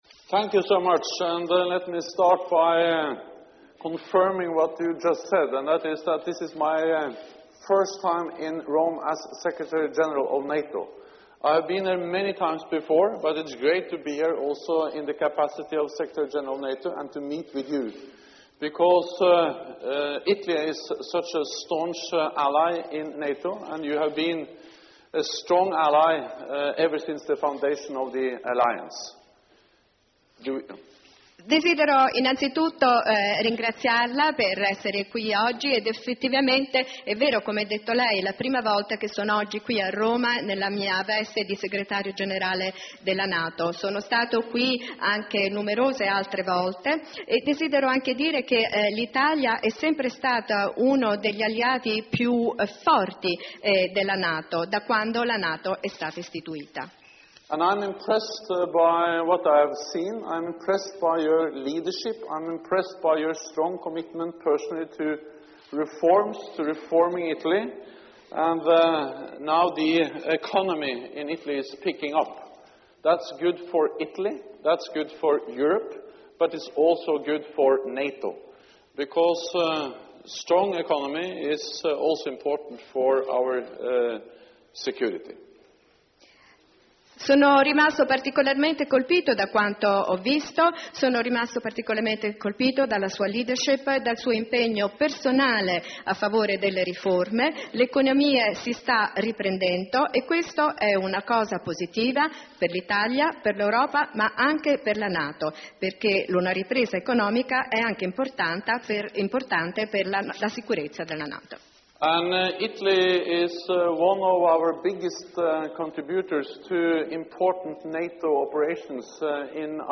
Opening remarks by NATO Secretary General Jens Stoltenberg at the joint press point with the Prime Minister of Italy, Matteo Renzi